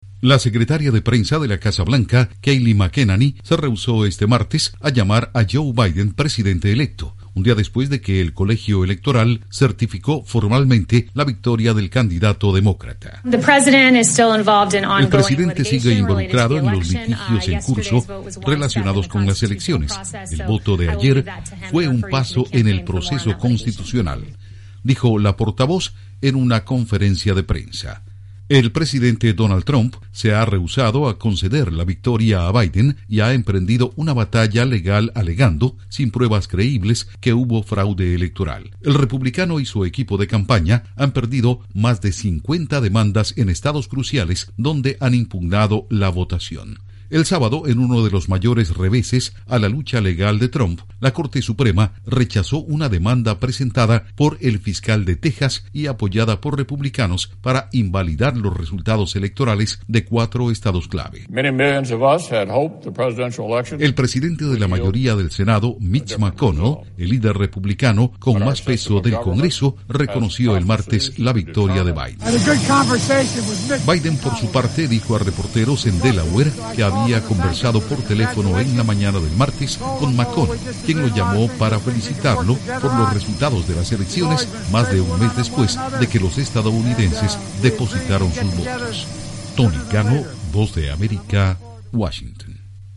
Portavoz de la Casa Blanca dice que certificación de victoria de Biden es solo "un paso". Informa desde la Voz de América en Washington